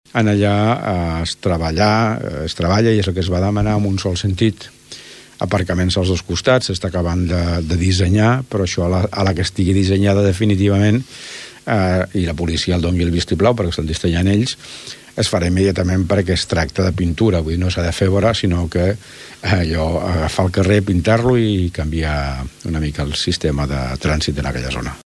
L’alcalde de Tordera, Joan Carles Garcia, ho detalla.
alcalde-sentit-cami-ral.mp3